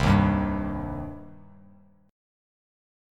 Cm Chord
Listen to Cm strummed